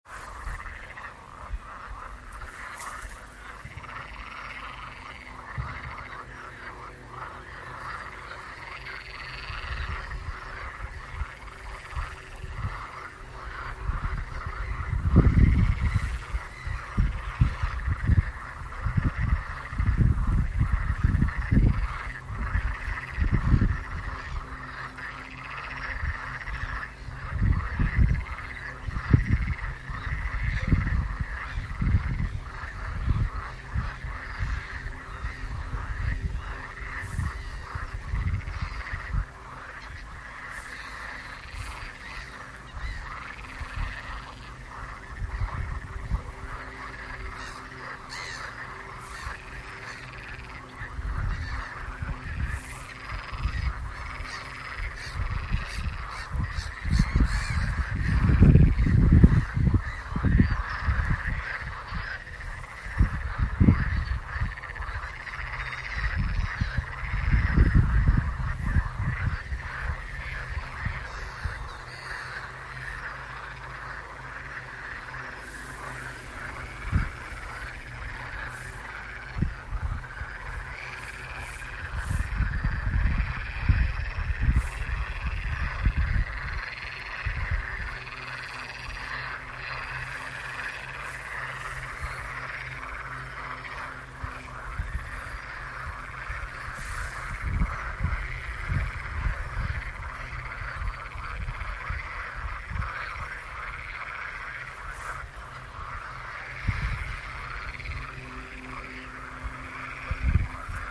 I found a pond near the village. It was the end of spring beginning of summer.
In this audio you can hear how someone are born while others die to become a food.
en Latvia, Aizpute